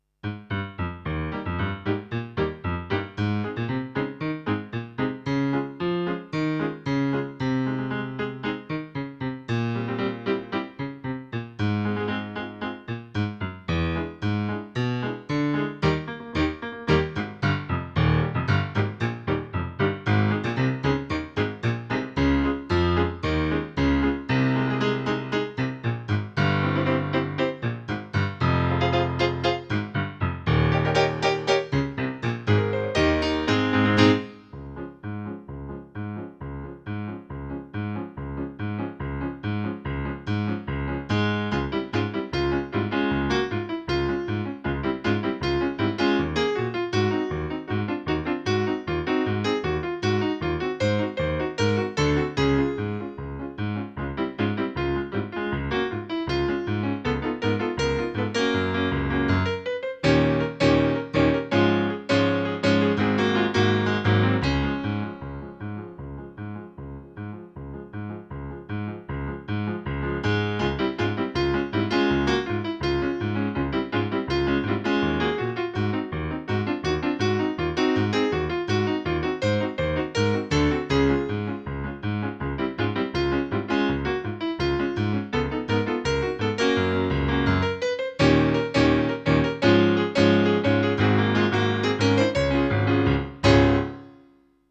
In terms of the composition, I like the “B” part well enough, and I’m quite happy with the “C” part: I enjoy the way the melody and chords are mixing in the right hand. The accelerando in the bridge between them is a bit annoying, but I can’t really help it: to my ear, the “C” part needs to be rather faster than the “B” part, so we have to get from one to the other somehow – unless I eventually write more march parts and can graft these two parts onto other bits with matching tempo.
A few missed notes are annoying, but I think you can imagine what they’re supposed to be; the other major flaw is the tempo: even though I had already slowed down compared to some earlier test recordings, listening back to it I now think I was still too fast in the “C” part (and didn’t even hold a consistent tempo either).
march-katowice.flac